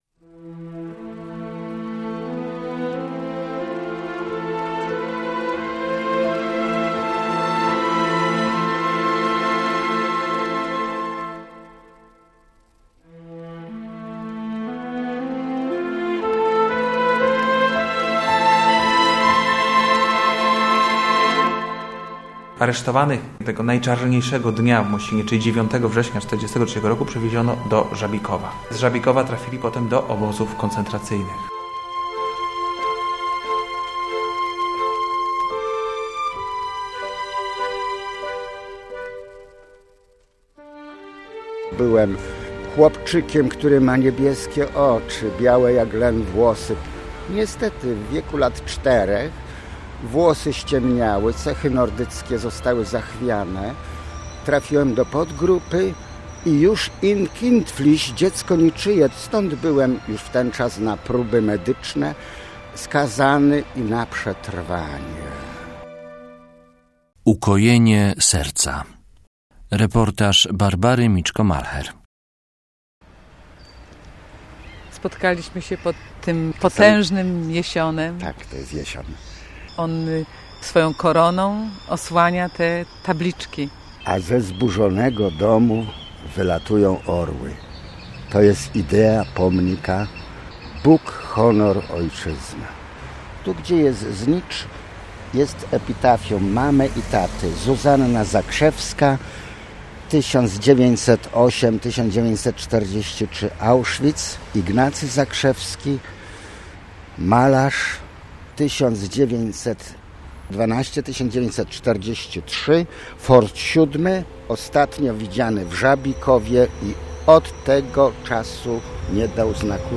Pomnik skupia ludzi - reportaż